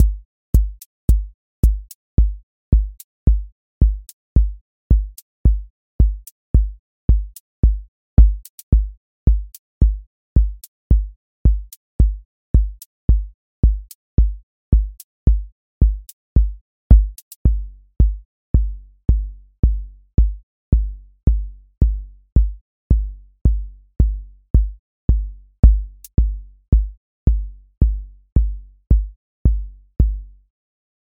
Four Floor Drive QA Listening Test house Template: four_on_floor April 17, 2026 ← Back to all listening tests Audio Four Floor Drive
four on floor
voice_kick_808 voice_hat_rimshot voice_sub_pulse